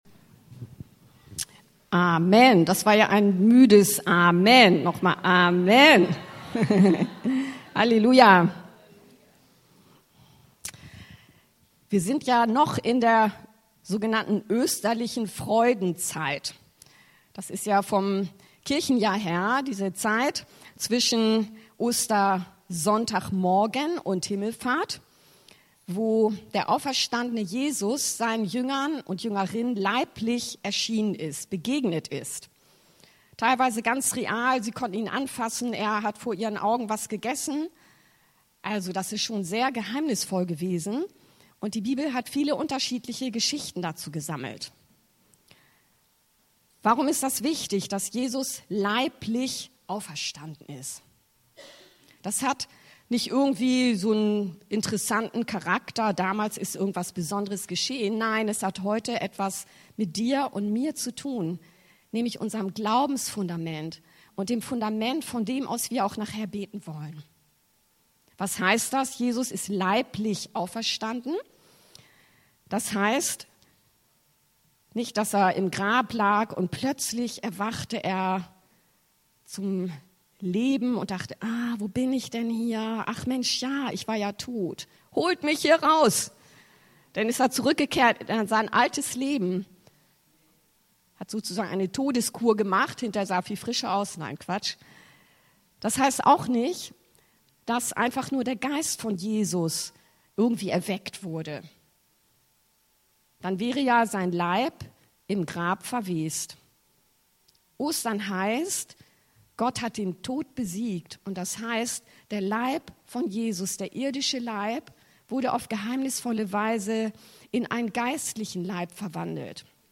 Jesus vergibt und heilt auch heute! LK. 5, 17-26 ~ Anskar-Kirche Hamburg- Predigten Podcast